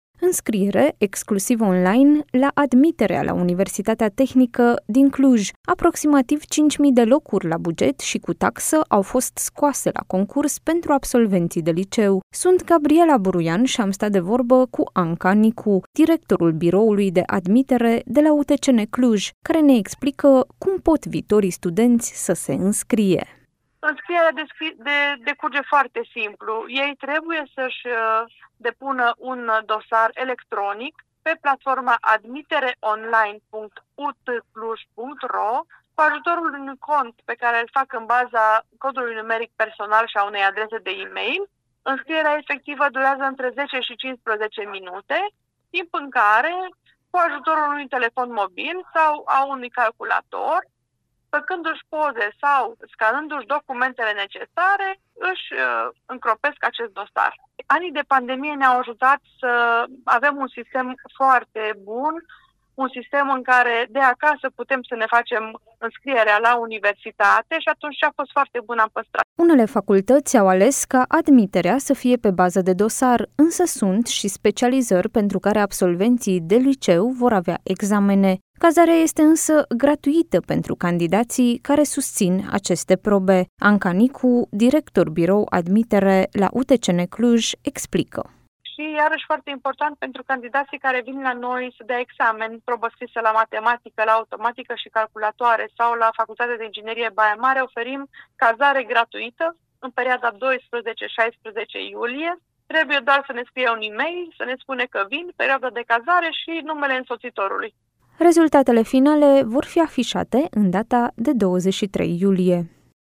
a stat de vorbă